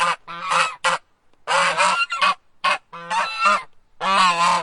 goose.ogg